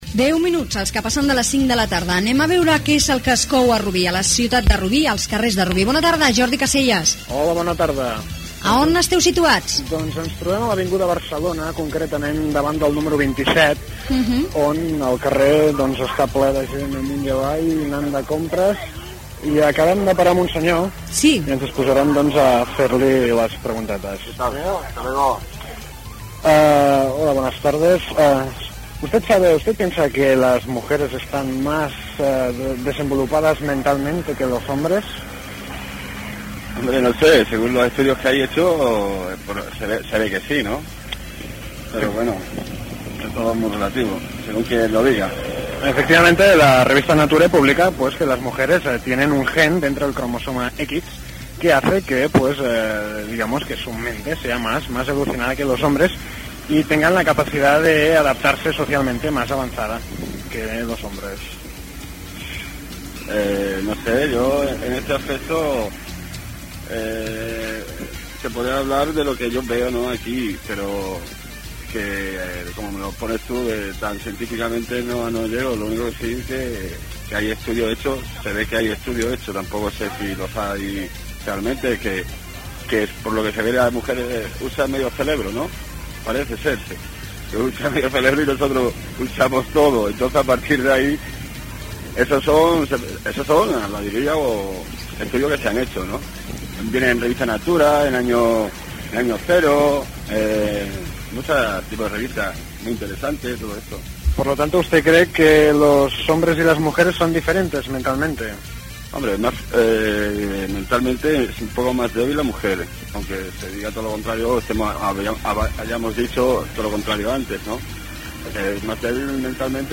Hora, connexió amb la unitat mòbil per fer preguntes sobre si les dones tenen una ment més desenvolupada que els homes
Entreteniment